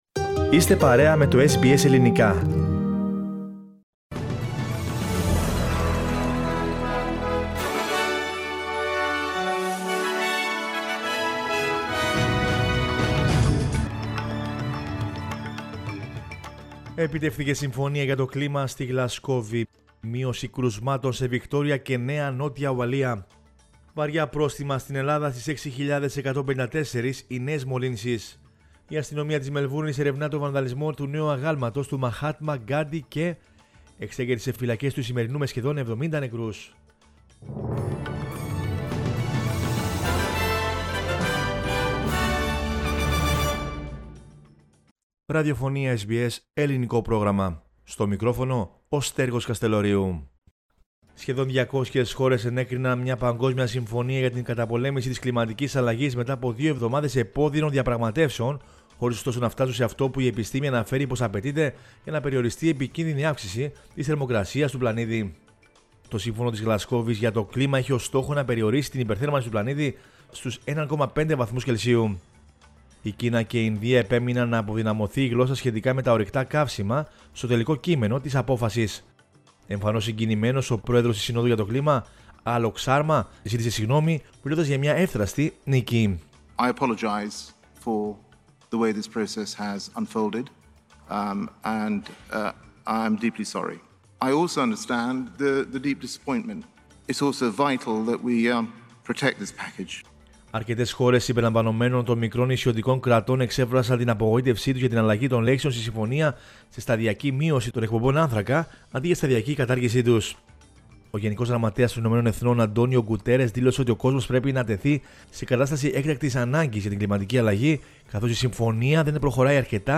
News in Greek from Australia, Greece, Cyprus and the world is the news bulletin of Sunday 14 November 2021.